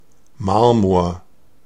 Ääntäminen
IPA : /ˈmɑːbəl/